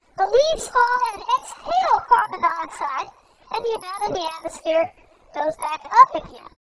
Speech Gender Conversion